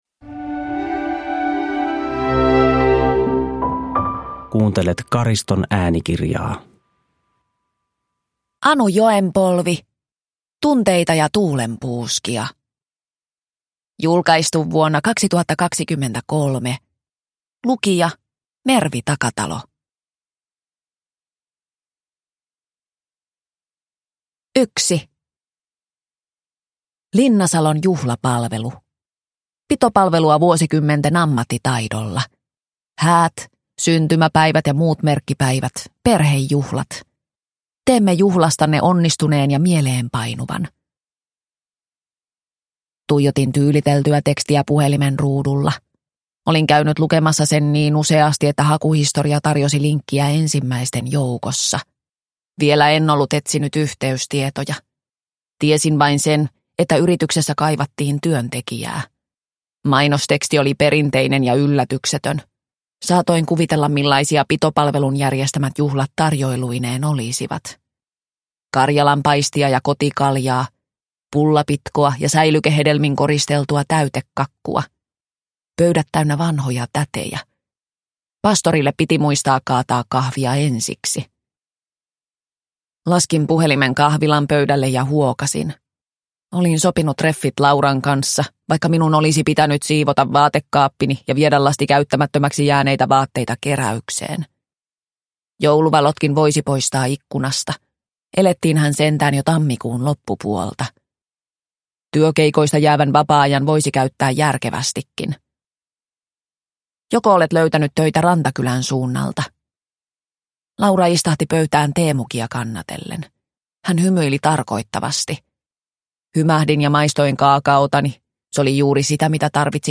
Tunteita ja tuulenpuuskia – Ljudbok – Laddas ner